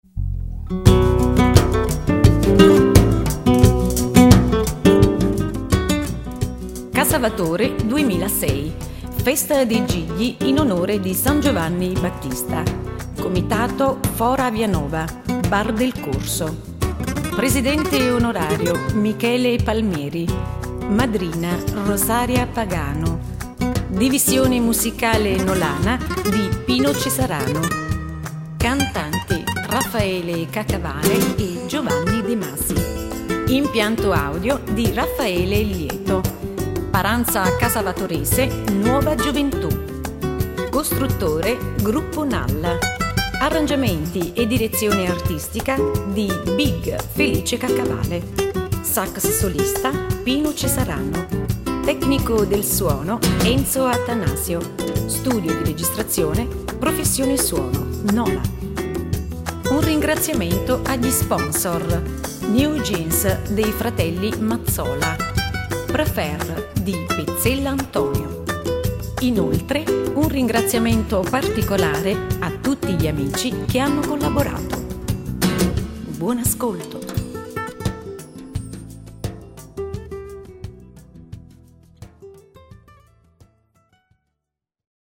Presentazione